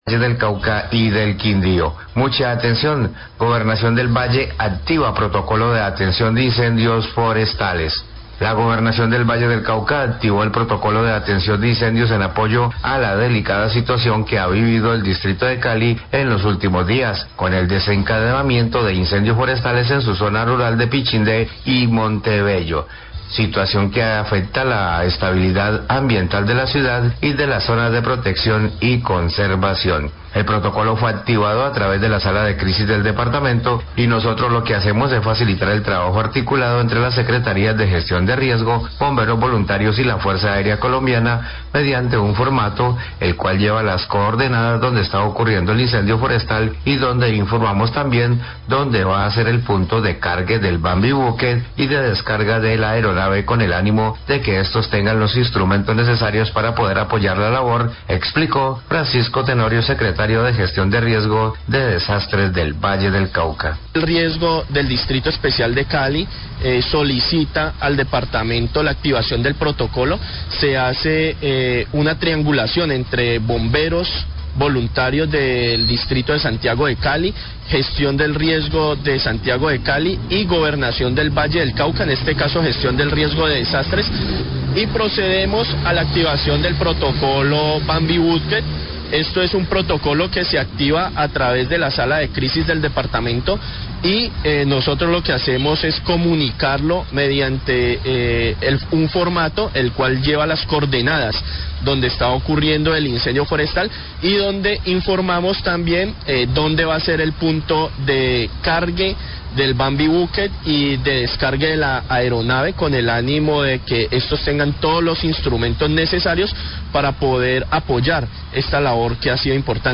Radio
La activación de protocolo de atención de incendios forestales en el Valle del Cauca para evitar la crisis fue realizada por el departamento de Gestión de Riesgo de Santiago de Cali, Bomberos voluntarios y la Gobernación del Valle del Cauca, con el Bambi Buque pretenden neutralizar estos fenómenos.  Habla francisco Tenorio, Secretario de Gestión de Riesgo del Valle del Cauca.